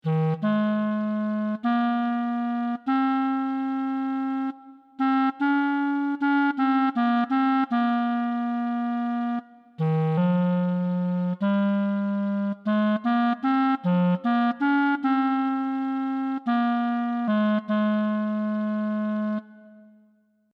As a first approach, we have assumed that a MIDI-controlled digital clarinet synthesiser based on physical models is a sufficiently good instrument model.